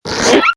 toon_decompress.mp3